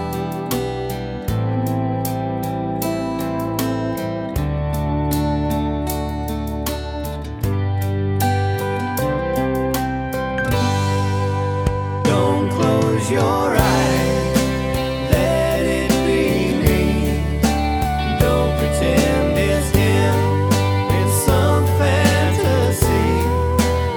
With Harmony Country (Male) 2:58 Buy £1.50